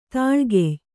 ♪ tāḷgey